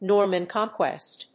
アクセントNórman Cónquest
音節Nòrman Cónquest